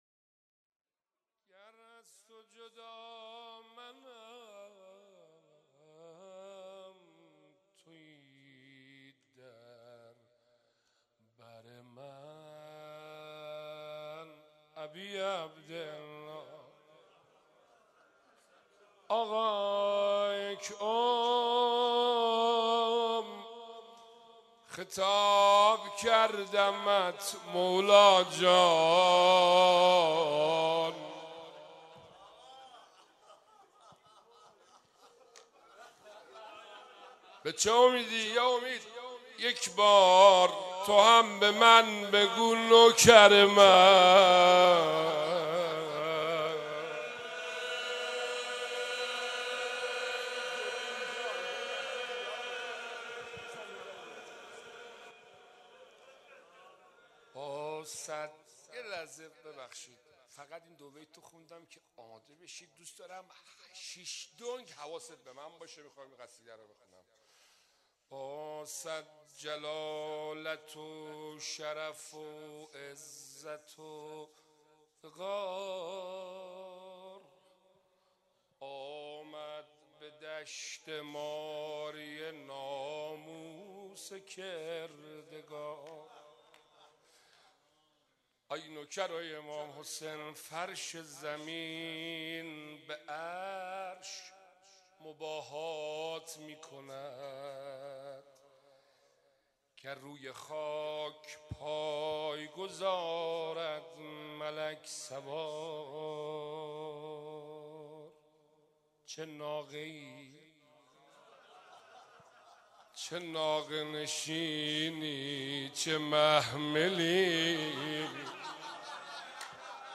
شب دوم محرم97